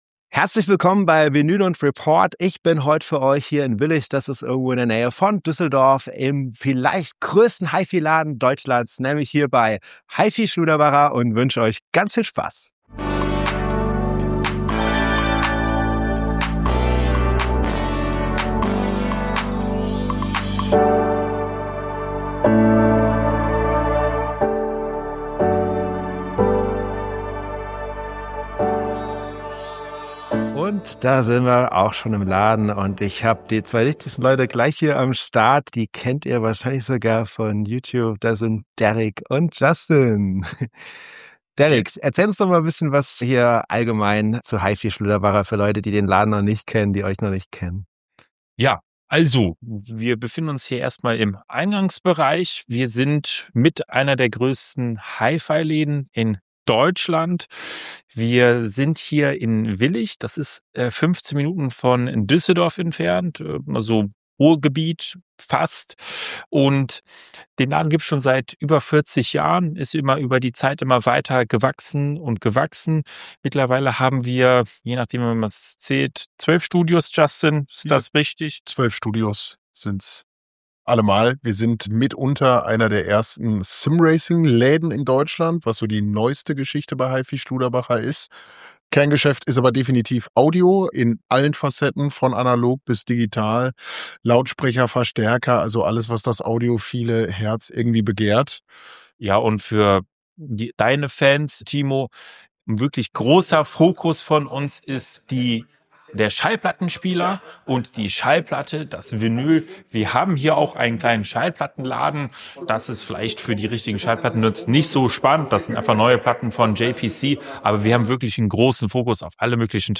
Im Video/Podcast nehme ich euch mit durch den Laden, zeige besondere Produkte und gebe euch einen Einblick hinter die Kulissen dieses beeindruckenden Hi-Fi-Hotspots in der Nähe von Düsseldorf…